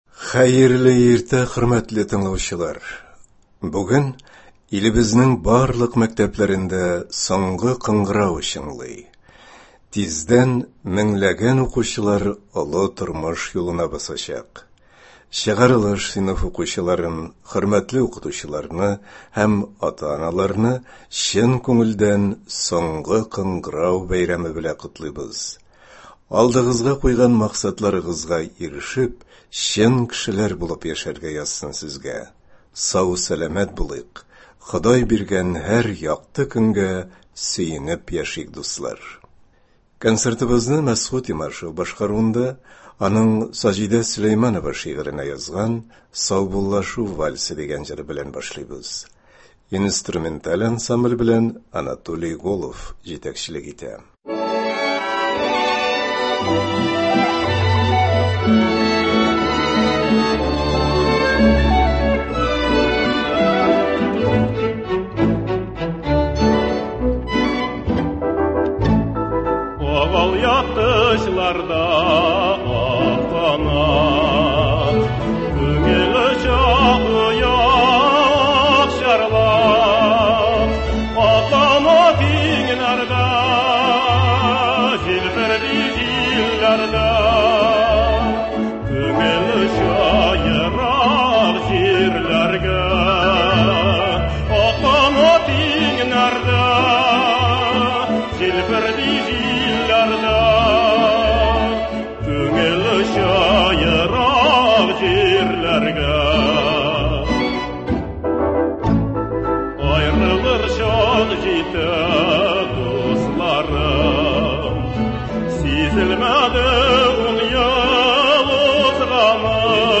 Бүгенге иртәнге концертта яраткан җырларыбыз яңгырый.